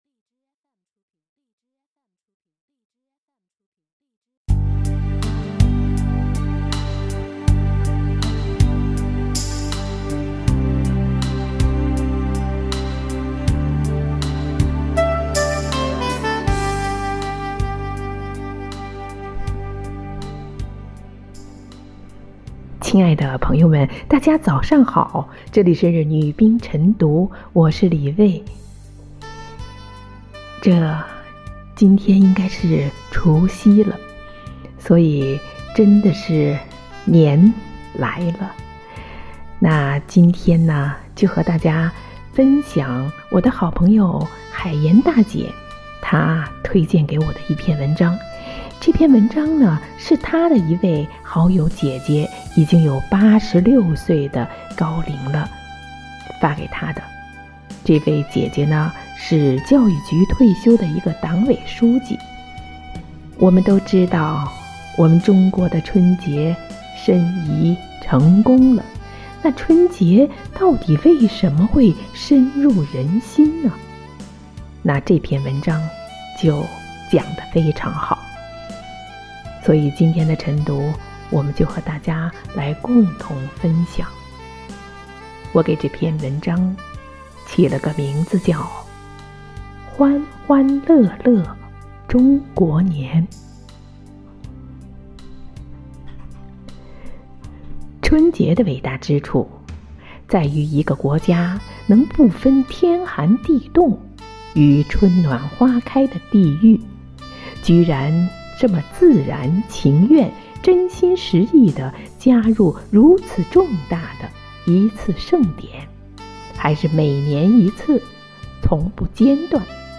每日《女兵诵读》欢欢乐乐中国年